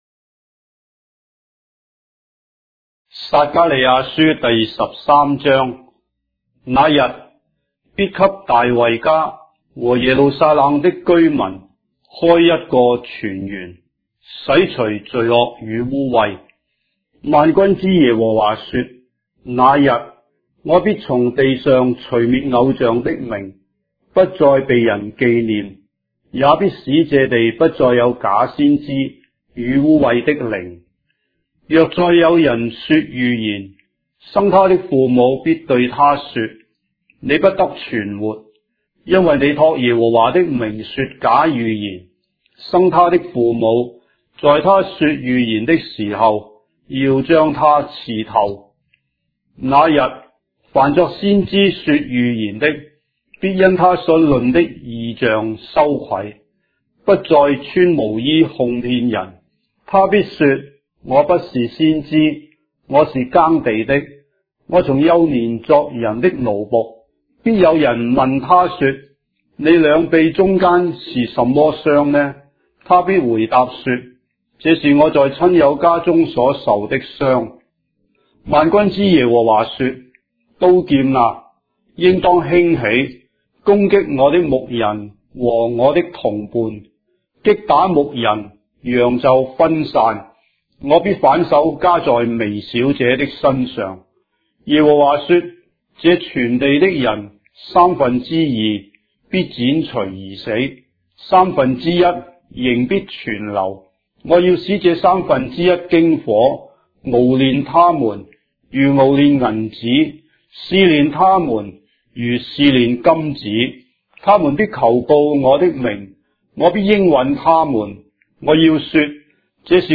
章的聖經在中國的語言，音頻旁白- Zechariah, chapter 13 of the Holy Bible in Traditional Chinese